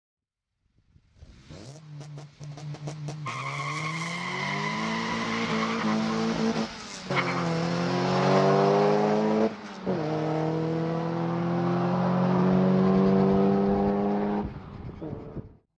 car-acceleration-26997.mp3